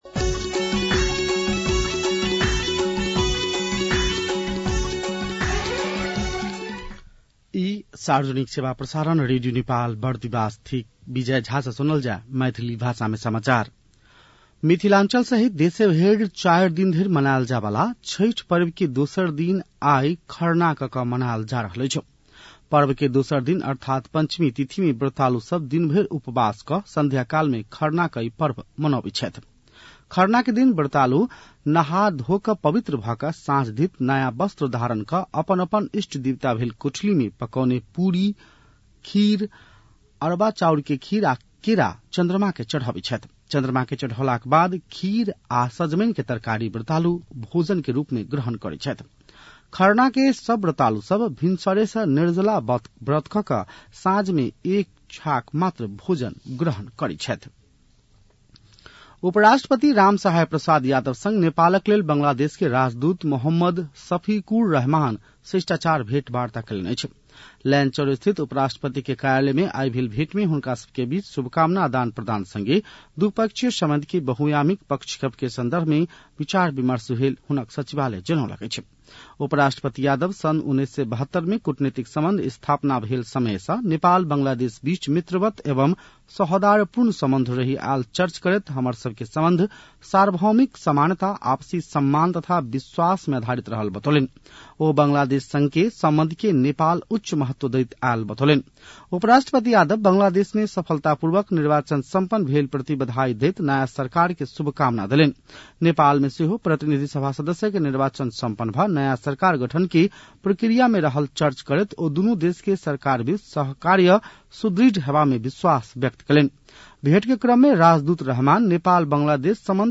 मैथिली भाषामा समाचार : ९ चैत , २०८२